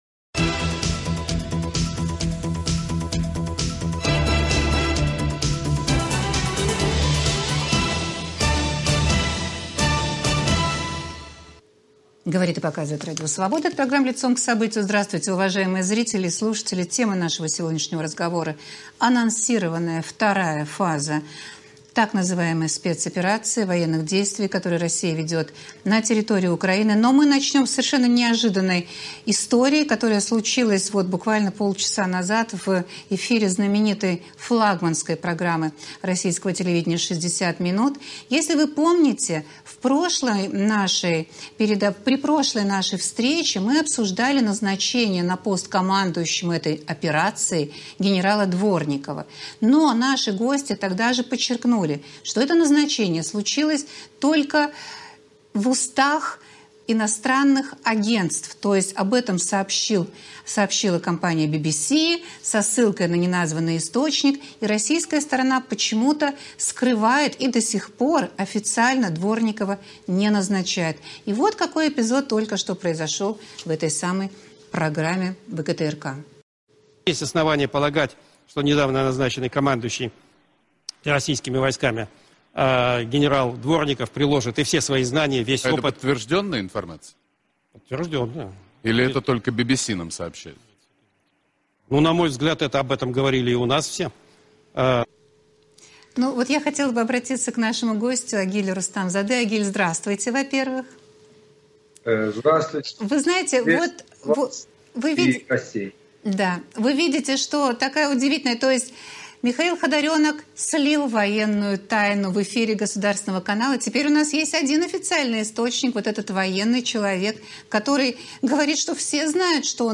"Вторая фаза" - какой она будет? В эфире военные эксперты